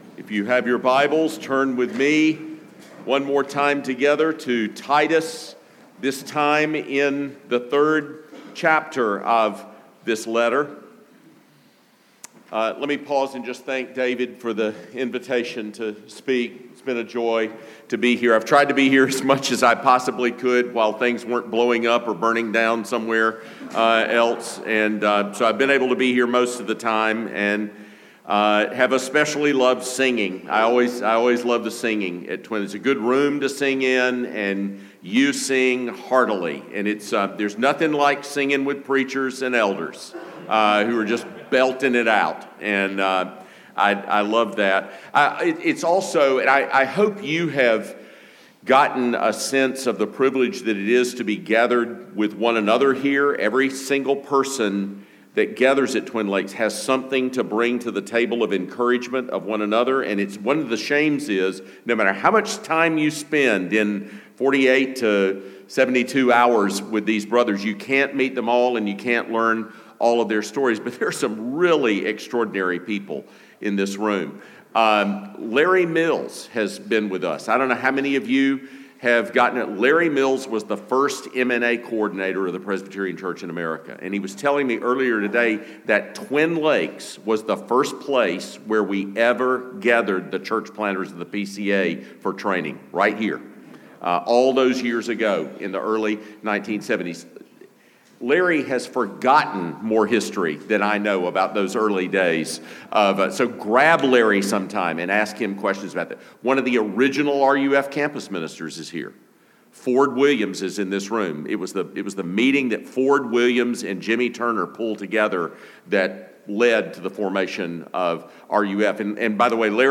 The Pastoral Epistles: Worship Service III – Saved, for Good Deeds